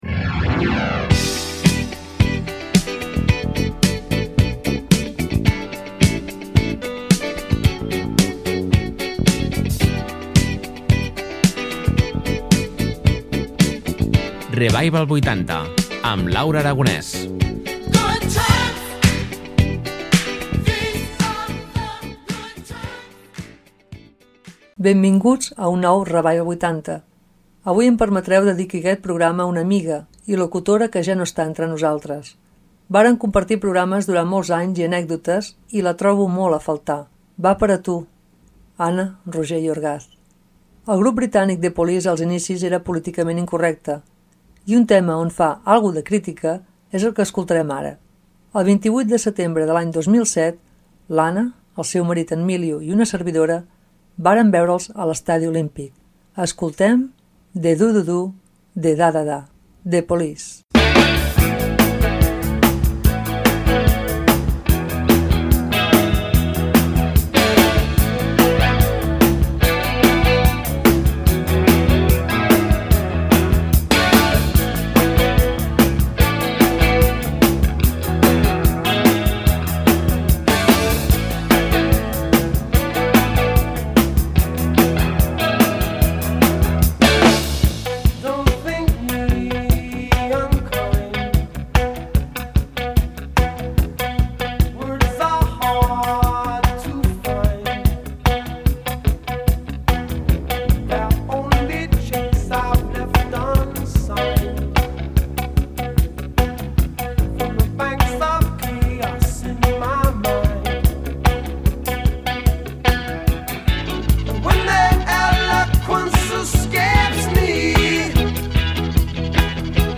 Escoltareu bons temes i molt coneguts.